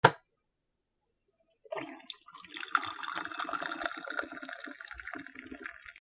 ポット お湯を注ぐ
『パコ チョロロロ』